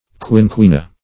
quinquina - definition of quinquina - synonyms, pronunciation, spelling from Free Dictionary Search Result for " quinquina" : The Collaborative International Dictionary of English v.0.48: Quinquina \Quin*qui"na\, n. [NL.